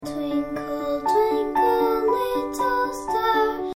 Apprenez une comptine anglaise pas à pas, d'un clic de souris